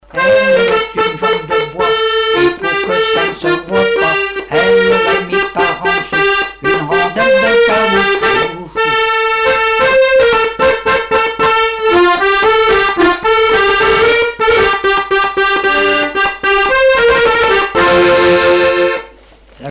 danse : marche
Répertoire du musicien sur accordéon chromatique
Pièce musicale inédite